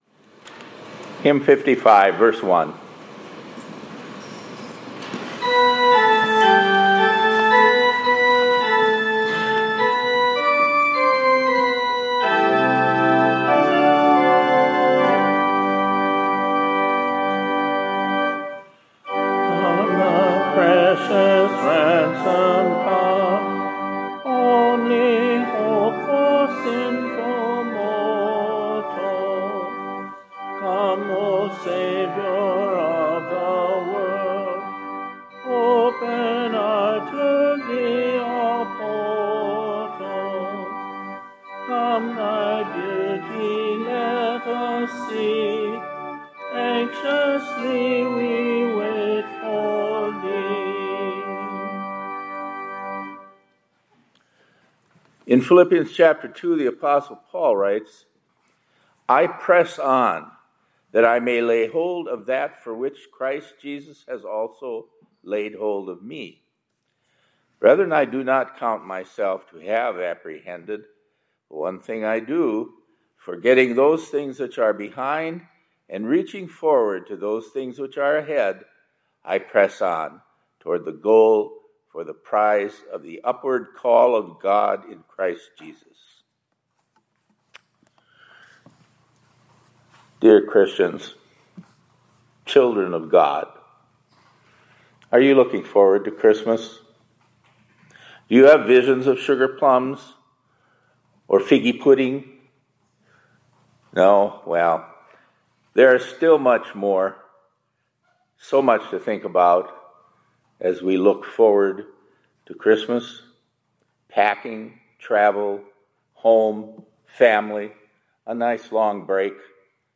2024-03-28 ILC Chapel — Christ Came to Save the…